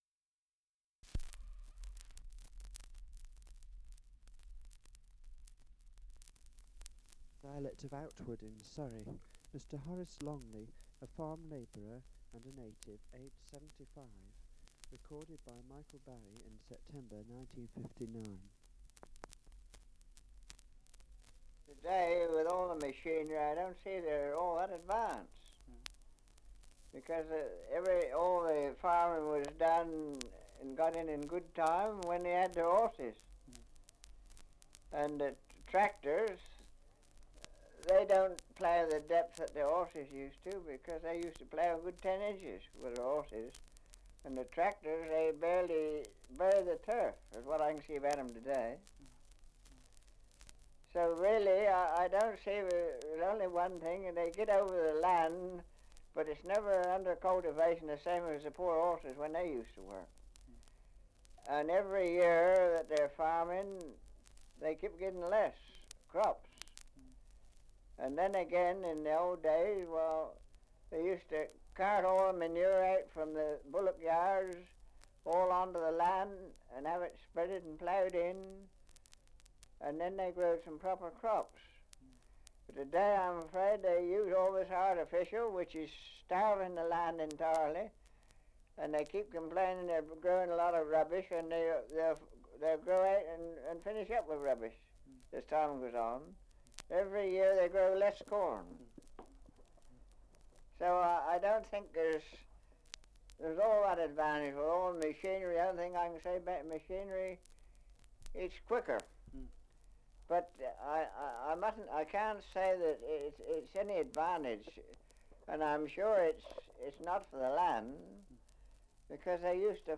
Survey of English Dialects recording in Outwood, Surrey
78 r.p.m., cellulose nitrate on aluminium